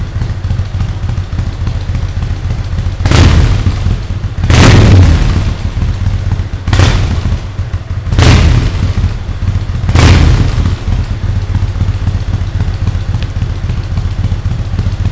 Auspuff-Sounds
Gaszucken
ms_gaszucken.wav